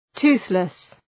Προφορά
{‘tu:ɵlıs}